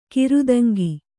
♪ kirudaŋgi